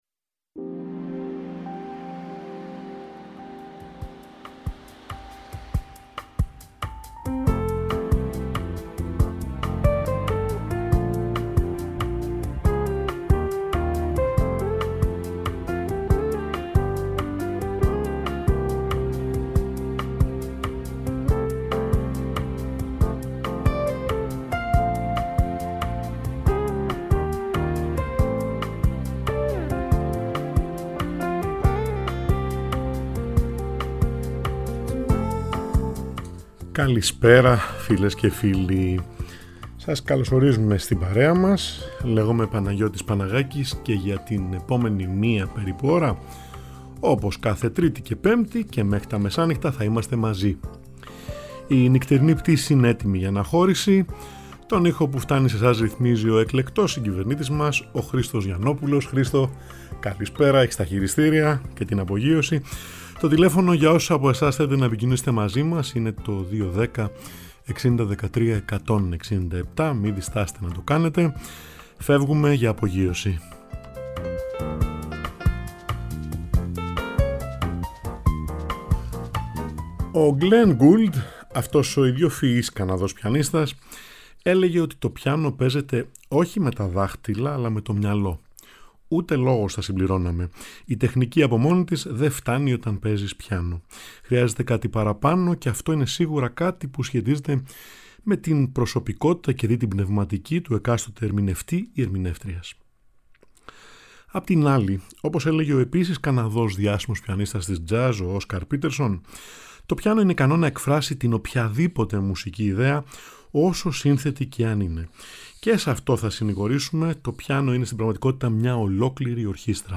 Είναι δυνατή η μεταγραφή για σόλο πιάνο (δυο χέρια) σπουδαίων έργων για ορχήστρα;
Η απάντηση στη «Νυχτερινή Πτήση» που απογειώνεται κάθε Τρίτη & Πέμπτη μία ώρα πριν από τα μεσάνυχτα, στο Τρίτο Πρόγραμμα 90,9 & 95,6 της Ελληνικής Ραδιοφωνίας